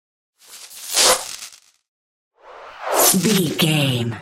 Whoosh sci fi disappear fast
Sound Effects
futuristic
whoosh